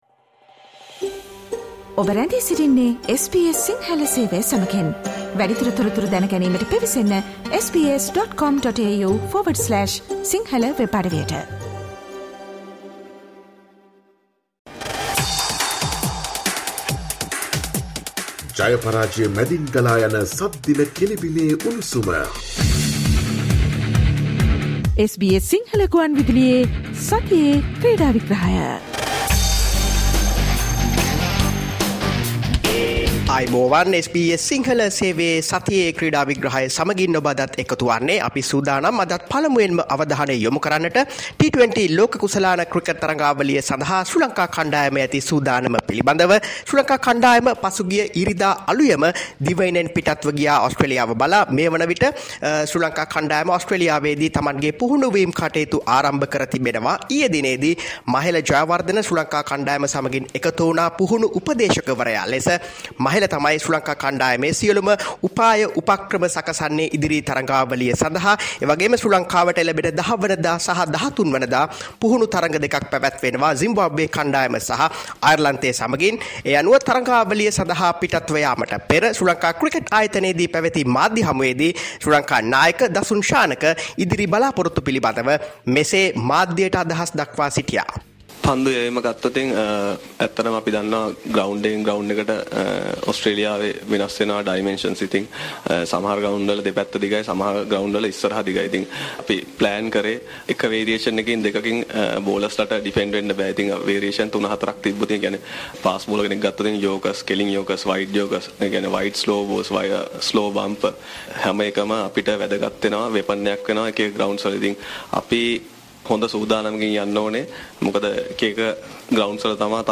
Wanidu has been identified as a prominent player by ICC for the T20 World Cup: Weekly Sports Wrap